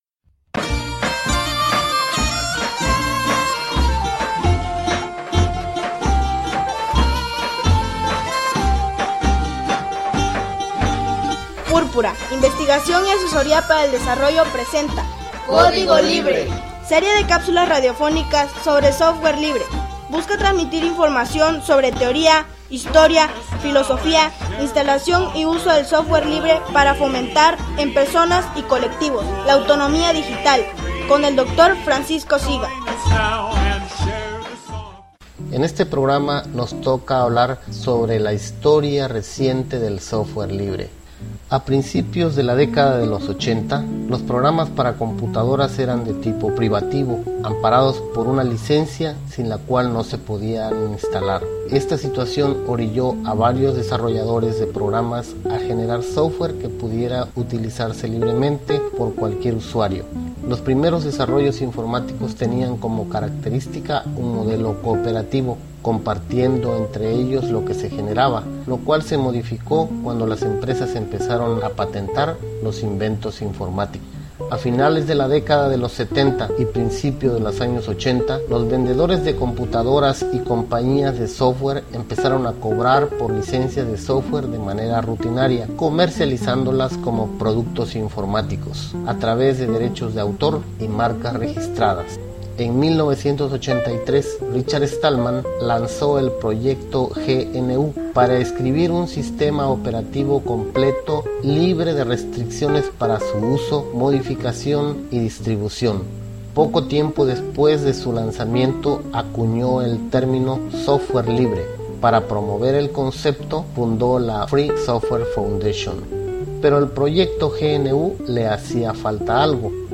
Serie de capsulas radiofónicas sobre Software Libre.